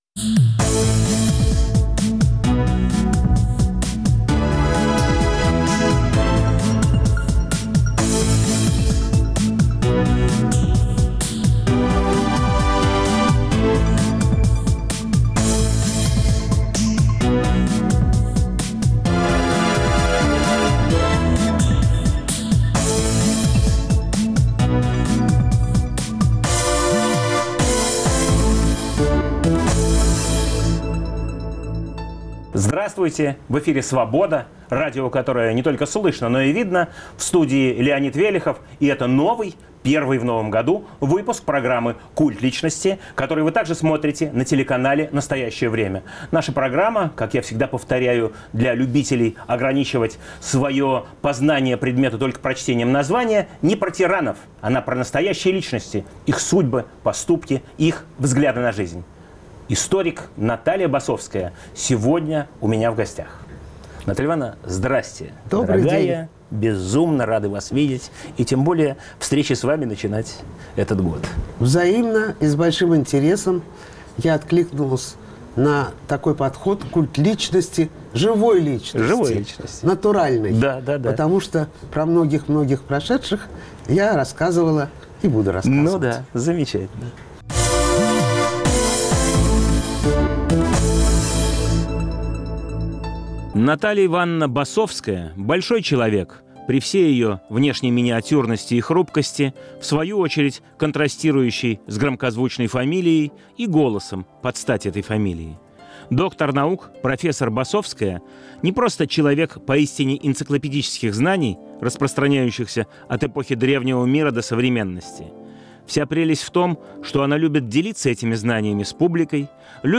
В студии нового выпуска "Культа личности"историк Наталия Басовская.